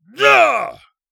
人声采集素材/男3战士型/ZS发力9.wav